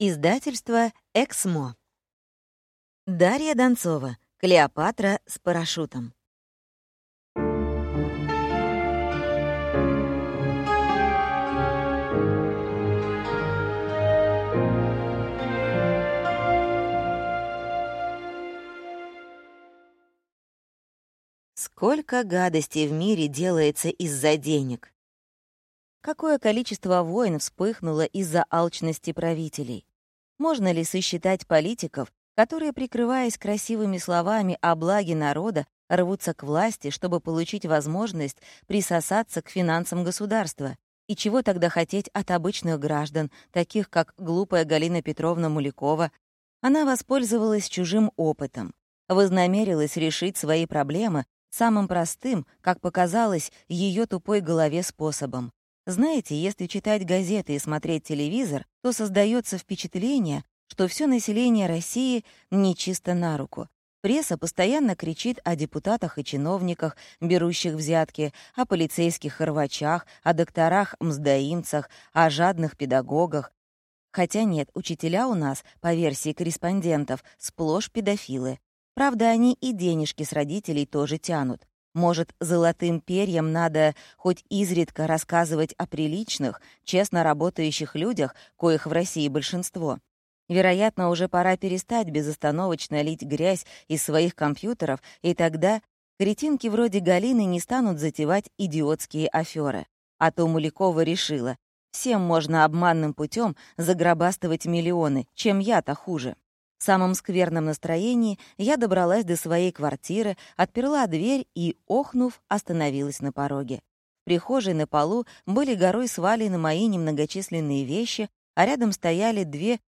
Аудиокнига Клеопатра с парашютом | Библиотека аудиокниг
Прослушать и бесплатно скачать фрагмент аудиокниги